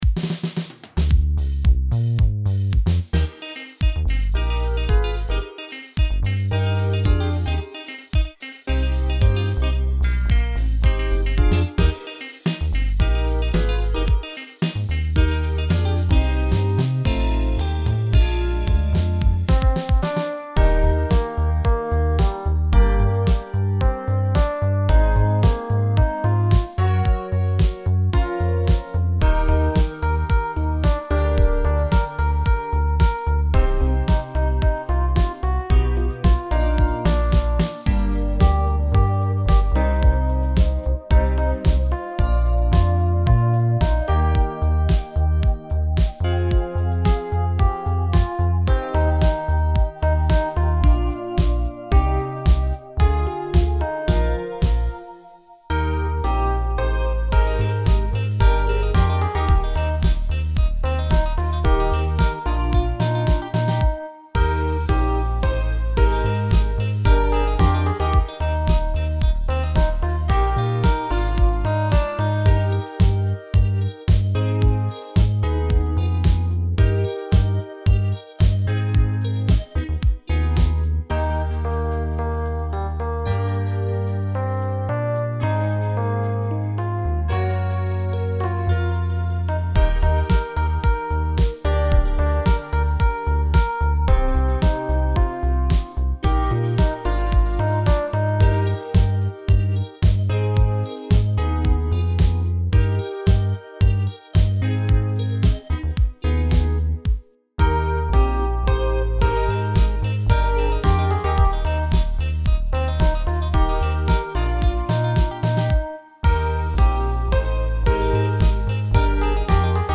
カフェでアイスコーヒー
cafe.mp3